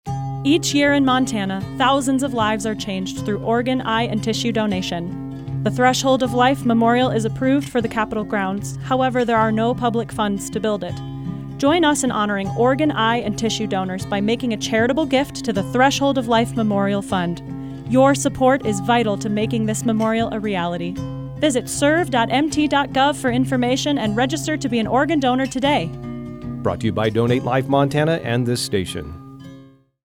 Public Service Announcements
Radio Spots